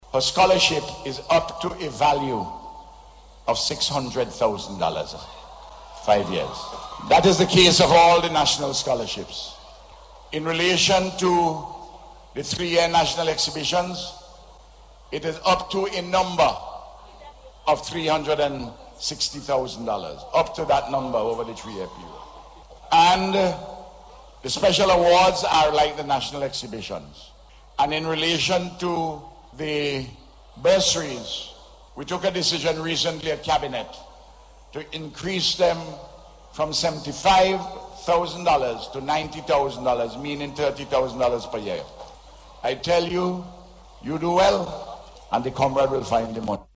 That’s according to Prime Minister and Minister responsible for Tertiary Education, Dr. Ralph Gonsalves, as he addressed the Schools Independence Rally yesterday.